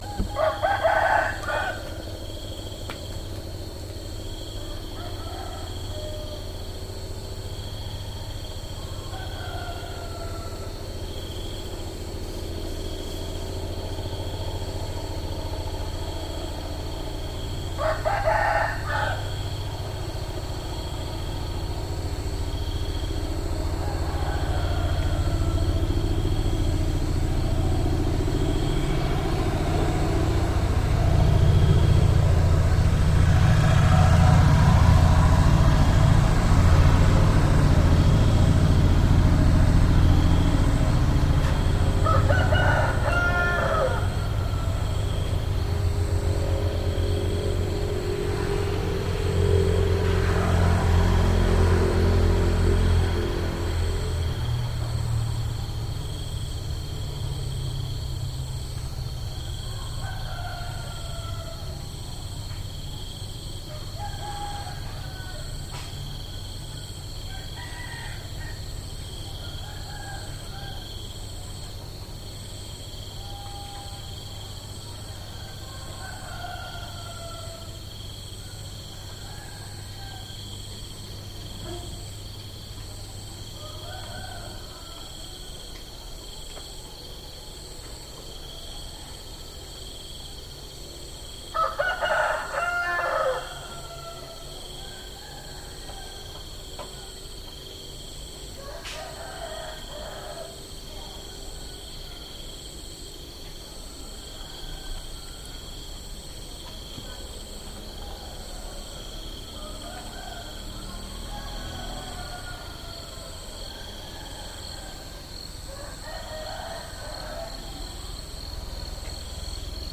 Night time in Vanv Vieng, Laos
Field recording taken in the village of Vanv Vieng, Laos - crickets, cockerels, and vehicles passing by.